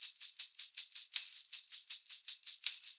80 TAMB2.wav